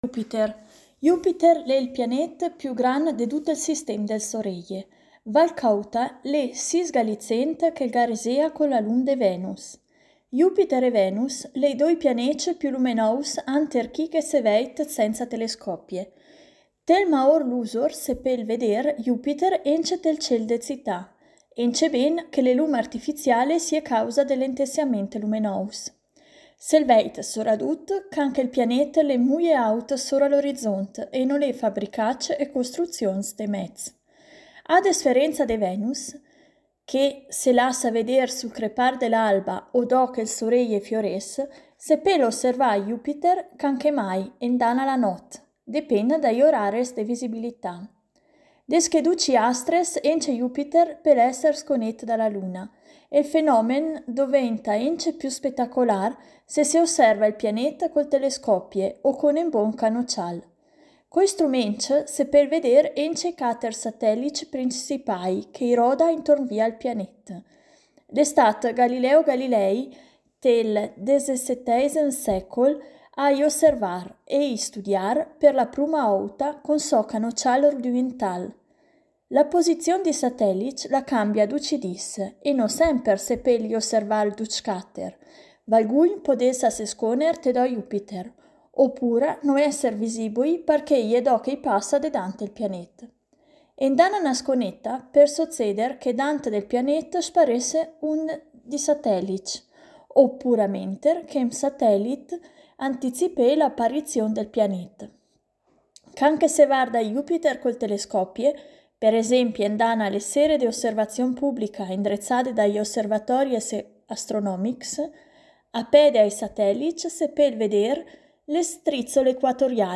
Queste parole sono scritte e lette ad alta voce in lingua ladina e in dialetto lumezzanese, ma i promotori del progetto confidano che altri territori organizzino analoghe attività in diverse parlate locali.